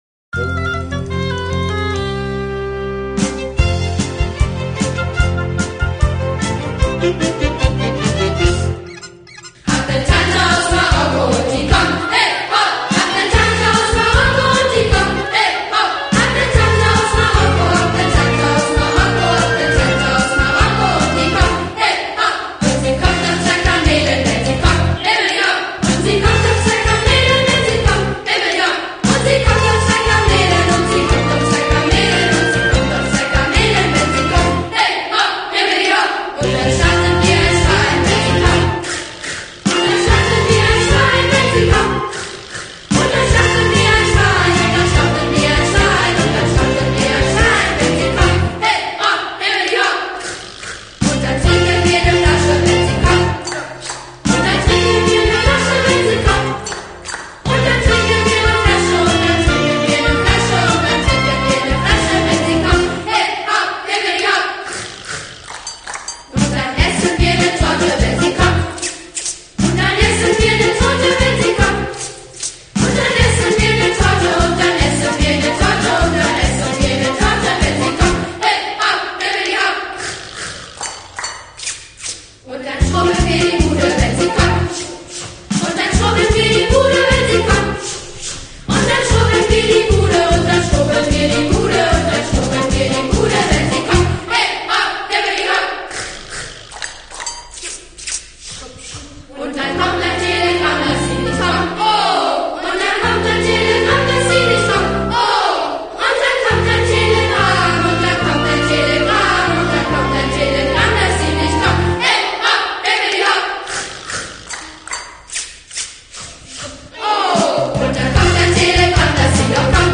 Audio Chor
Hab-ne-Tante-aus-Marokko_Chor.mp3